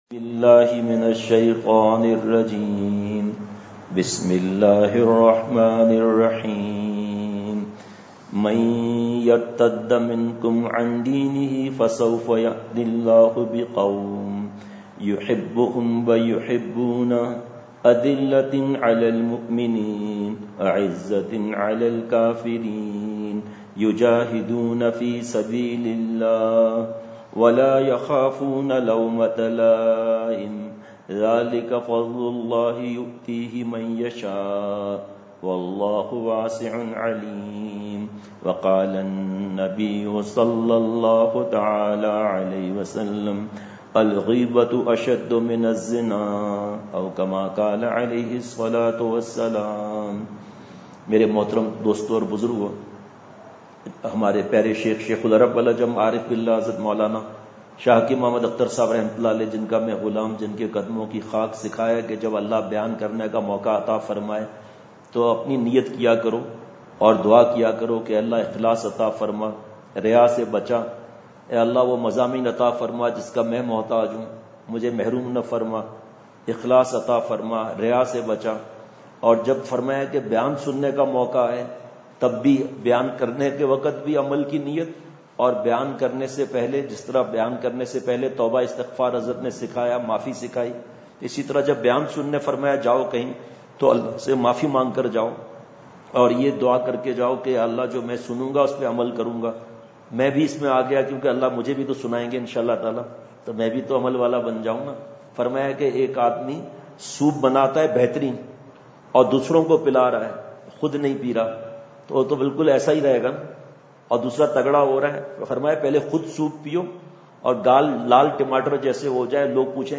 سفرساؤتھ افریقہ ۲۰۱۸ء ، بعد مغرب،۱۴ دسمبر : جامعہ مسجد Actonvill !